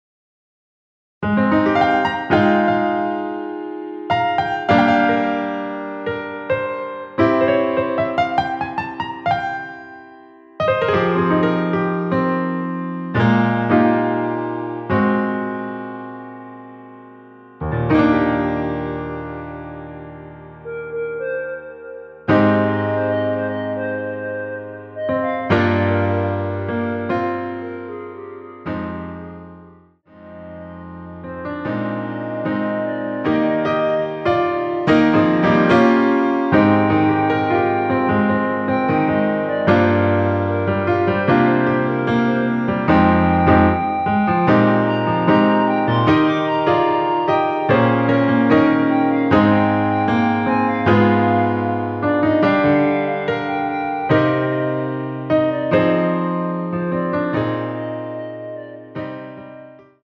원키에서(+3)올린 멜로디 포함된 MR 입니다.(미리듣기 참조)
Eb
앞부분30초, 뒷부분30초씩 편집해서 올려 드리고 있습니다.
중간에 음이 끈어지고 다시 나오는 이유는